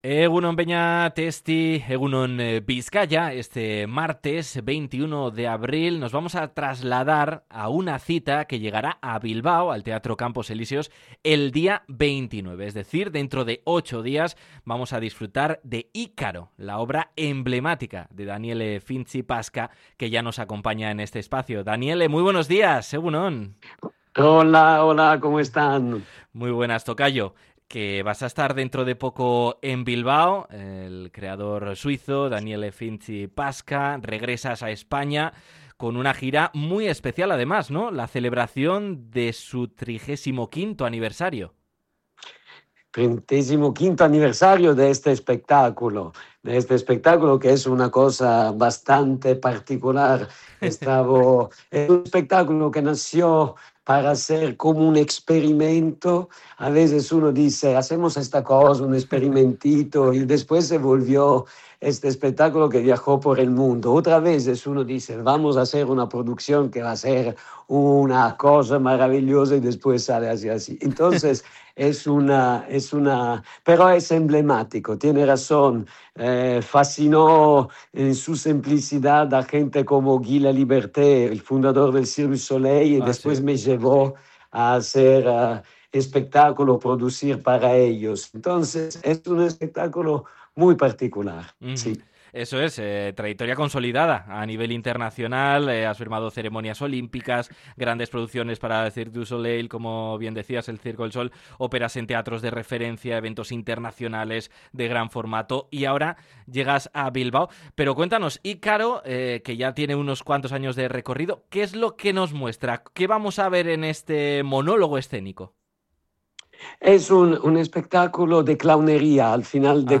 Hablamos con el creador suizo que presenta en el Teatro Campos Elíseos este montaje en su 35º aniversario